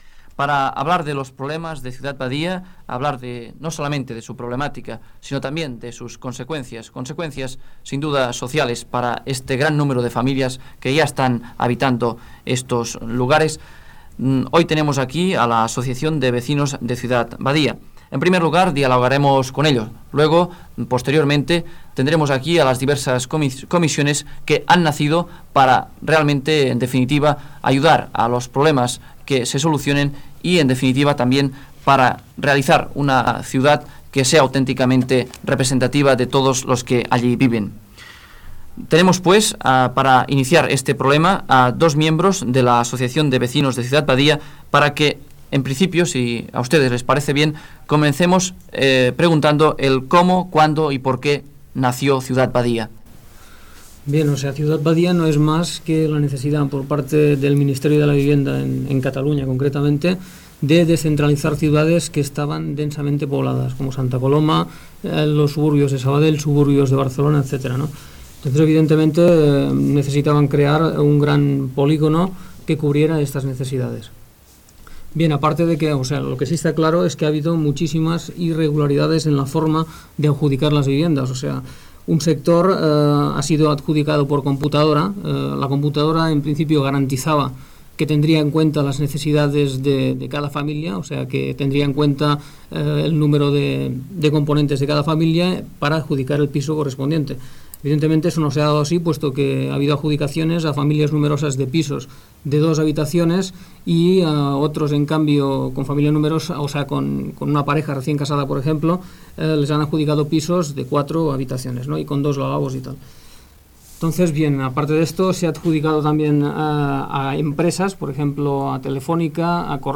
Espai dedicat a Ciudad Badía (anys després Badia del Vallès), amb la participació de dos membres de l'Asociación de Vecinos de Ciudad Badía, i de la comissiò de premsa i propaganda i de la comissió gestora Gènere radiofònic Informatiu